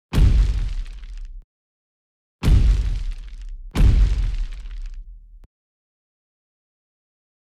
Thumps, Clangs and Booms - in space!
big boom clang compact concrete creepy dark dense sound effect free sound royalty free Memes